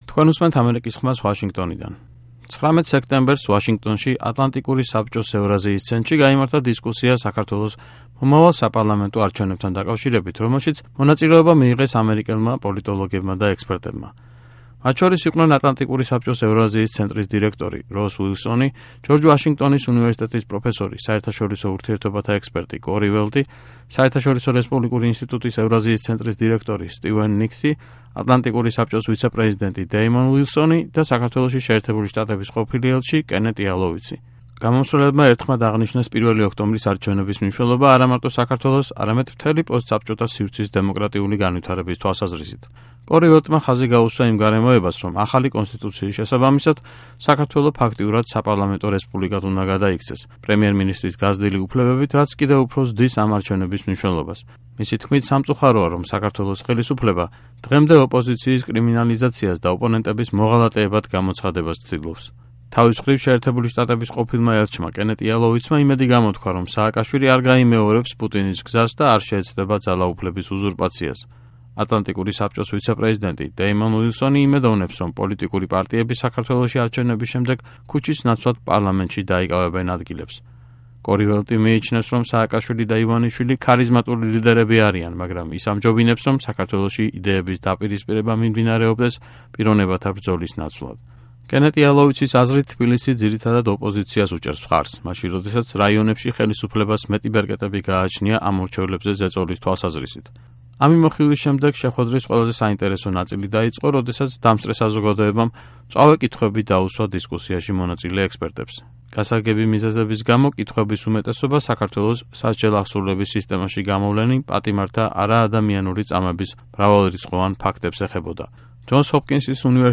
დისკუსია ატლანტიკურ საბჭოში